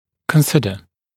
[kən’sɪdə][кэн’сидэ]рассматривать, считать, полагать